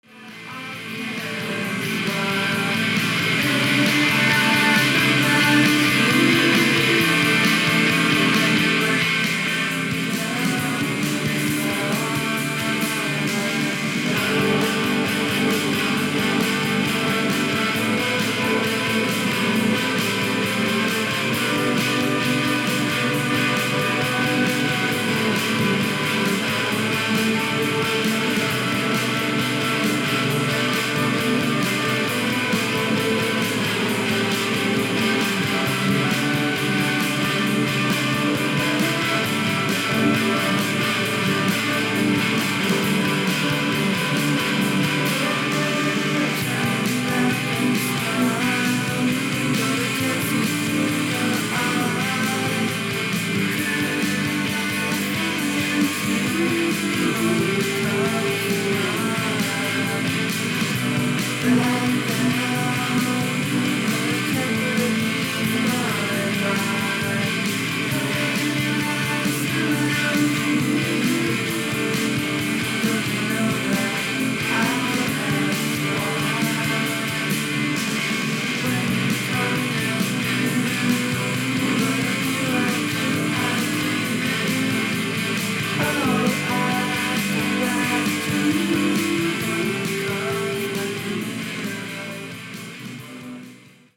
しっかりノイジーなんだけど、不思議とハマる（笑）